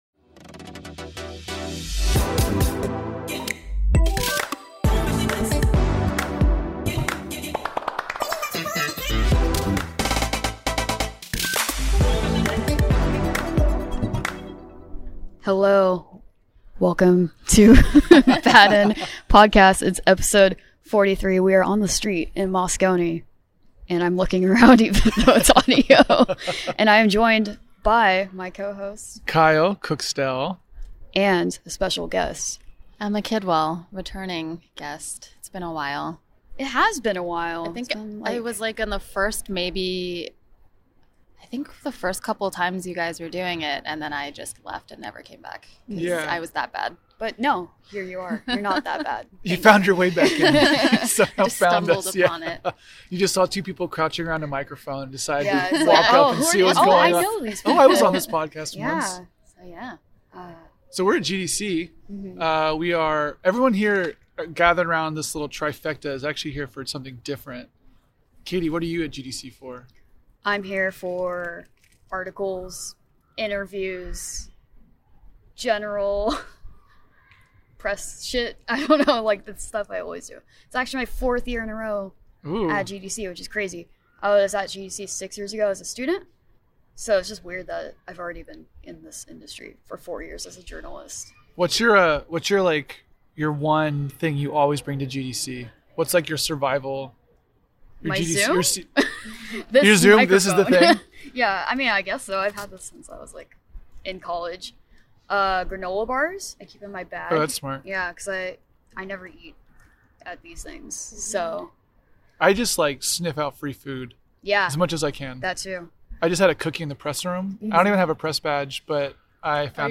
BAD END is live from Game Developers Conference in San Francisco, CA!